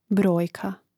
brȏjka brojka